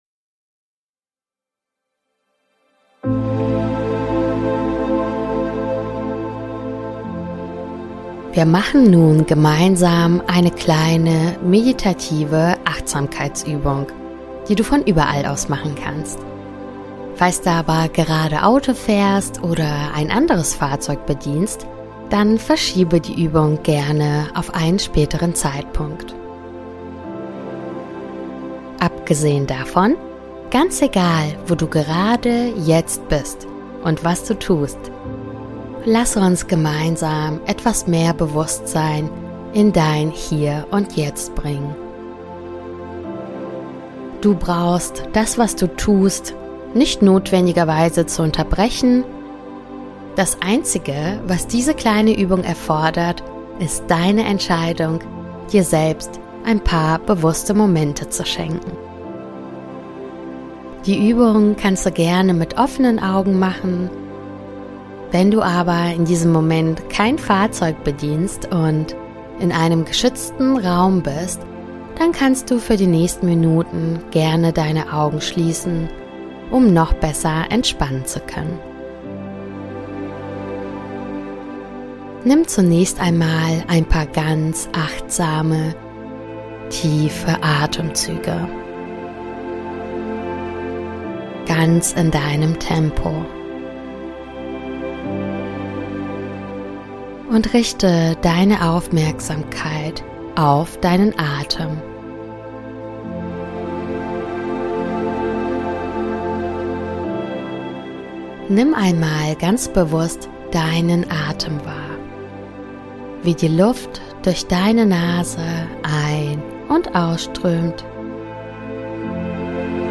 Achtsamkeitsübung